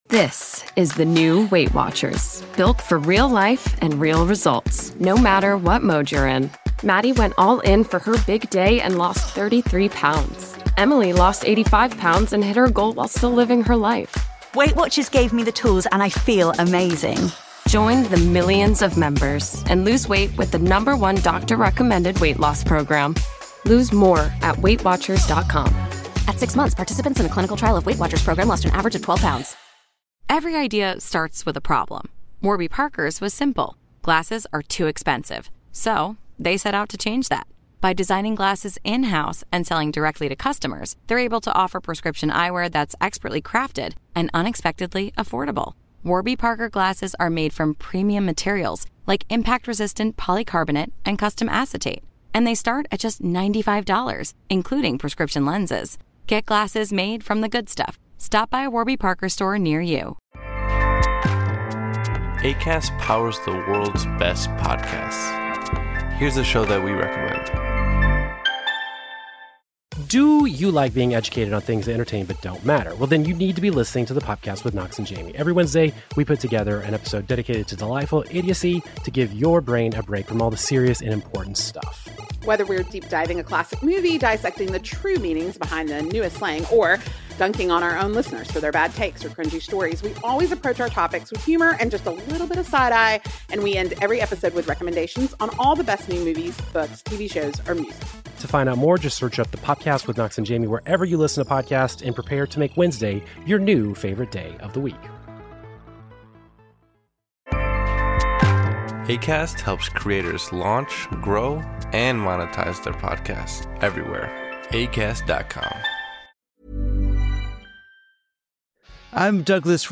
Douglas Rushkoff sits down with legendary comic writer and chaos magician Grant Morrison for a deeply urgent conversation about reclaiming reality.